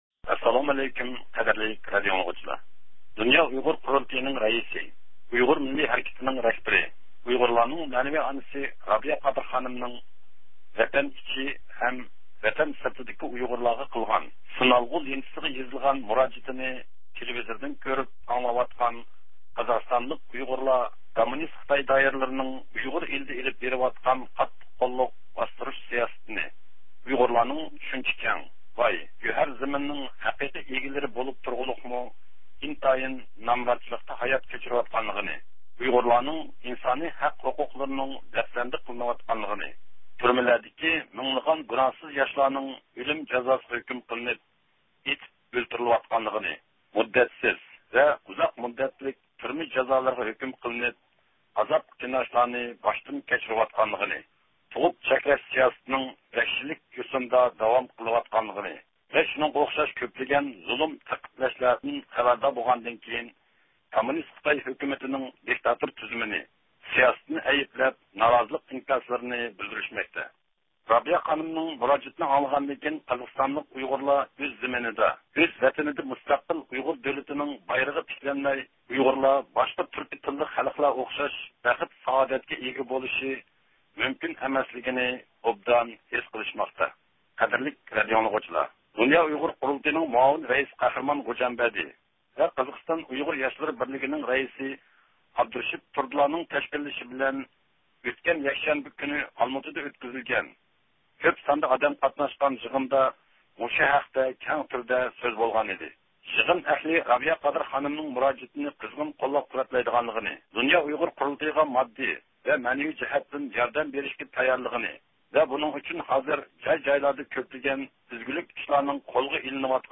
مۇراجىتى توغرىسىدا سۆھبەت